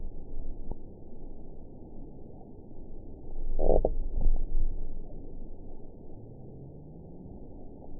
event 918732 date 12/10/23 time 10:24:22 GMT (1 year, 11 months ago) score 7.39 location TSS-AB05 detected by nrw target species NRW annotations +NRW Spectrogram: Frequency (kHz) vs. Time (s) audio not available .wav